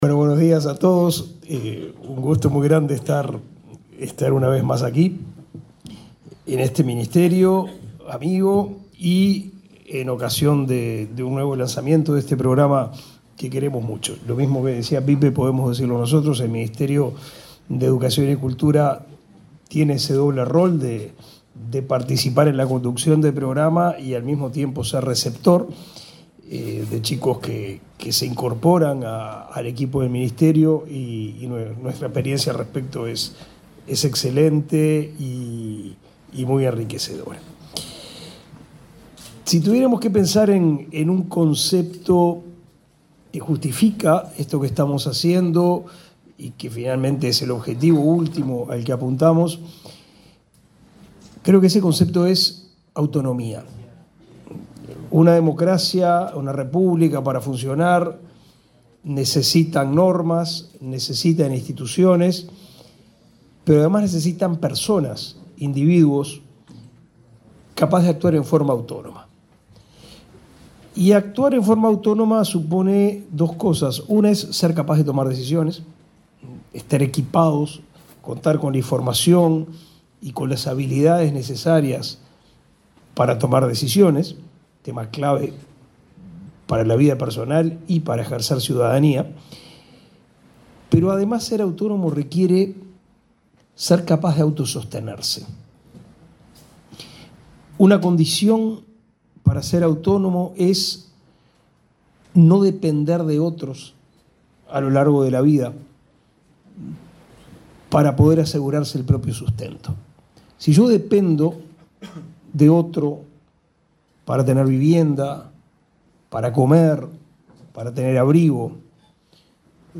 Palabras de autoridades en el Ministerio de Trabajo
Este jueves 17, el ministro de Educación y Cultura, Pablo da Silveira, y su par de Trabajo, Pablo Mieres, participaron en la presentación de la 12.ª